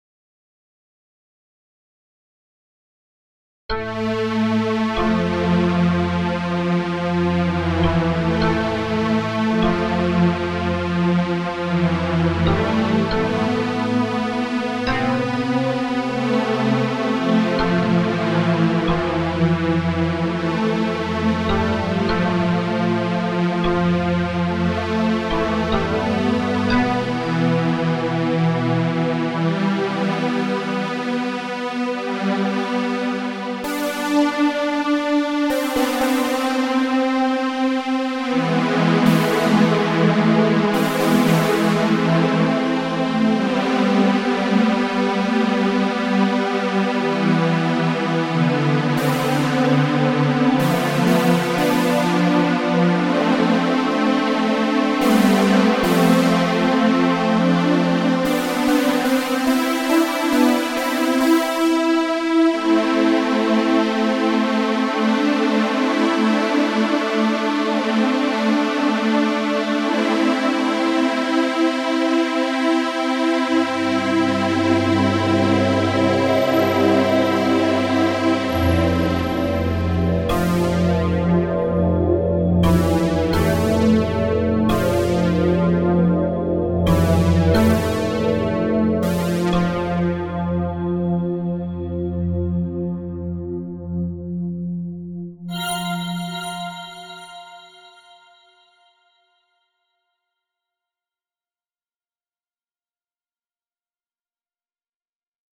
Also I sort of messed up a few parts on this so please excuse my clumsiness at the keyboard for this one. I was rushing myself. x3 143 Views 0 Favorites 0 Comments General Rating Category Music / Miscellaneous Species Unspecified / Any Gender Any Size 50 x 50px File Size 1.61 MB Keywords music synth atmospheric synthesizer ambient meditation new age techno trance orchestral